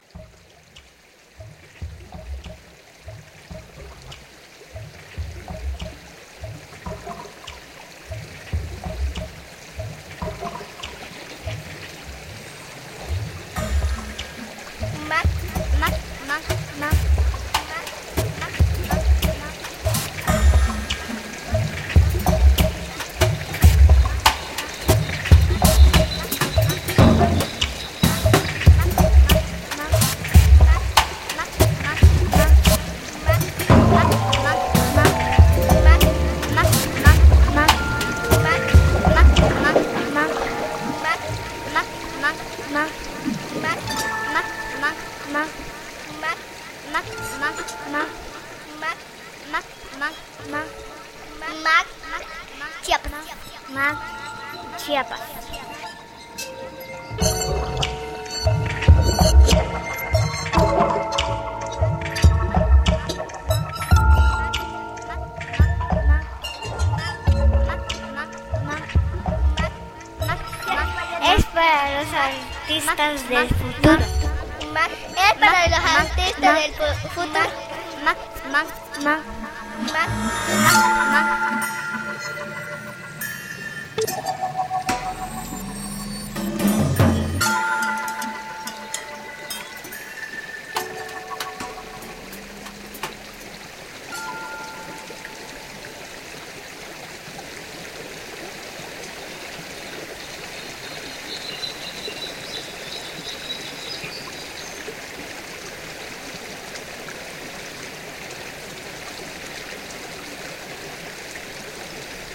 Promocional para radio del MAC Chiapas (Museo de Arte Contemporáneo de Chiapas). Este museo presenta una diversidad de opiniones tanto en escultura, plástica, electrónica y sonora que en un futuro no muy lejano será arte del pasado, así que este museo es de los artistas del futuro.